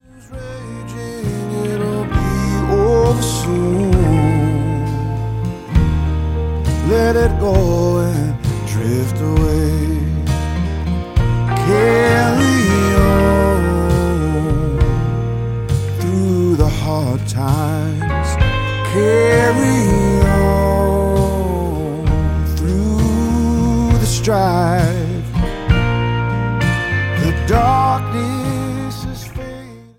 a limitless howl